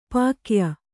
♪ pākya